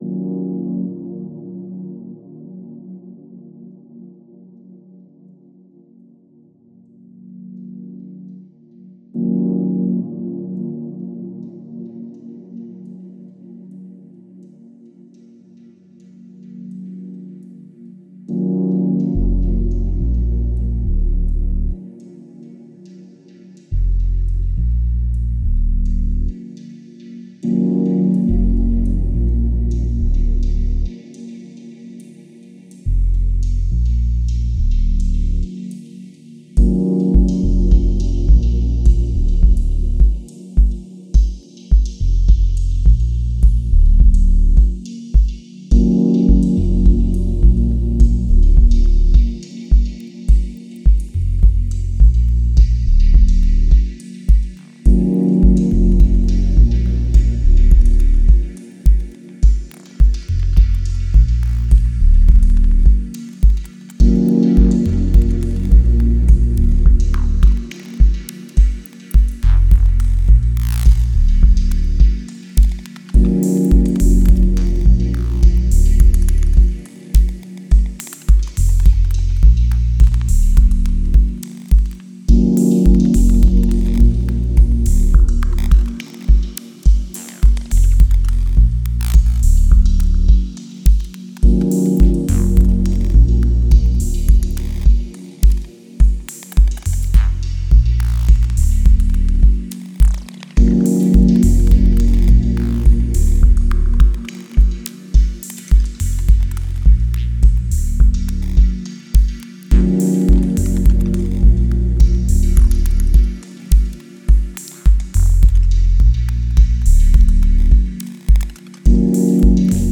Genre: Ambient/Deep Techno/Dub Techno.